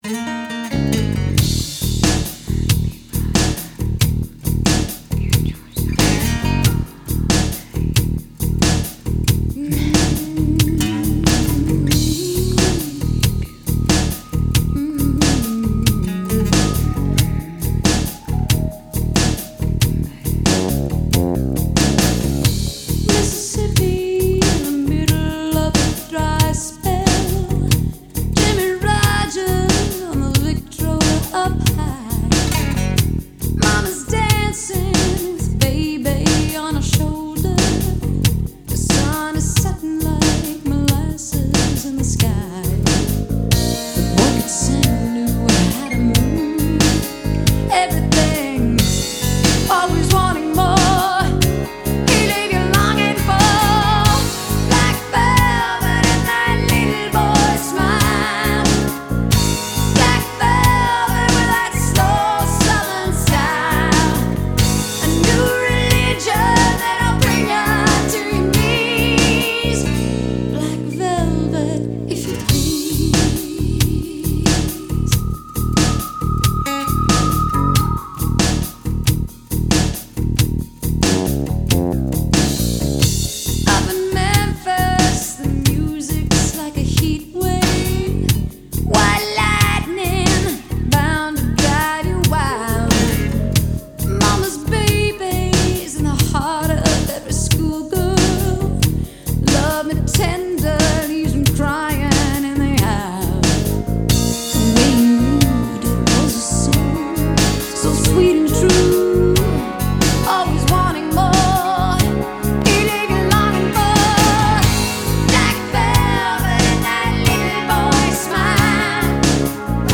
Жанры: рок, поп